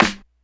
snare03.wav